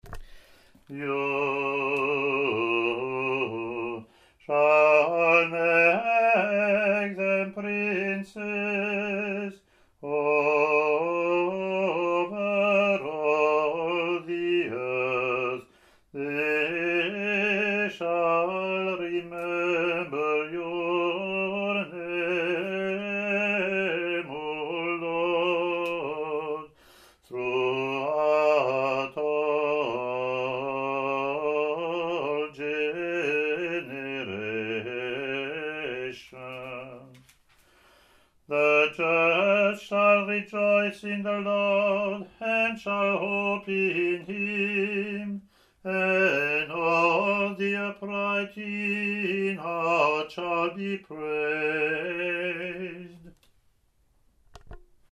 English antiphon – English verseLatin antiphon)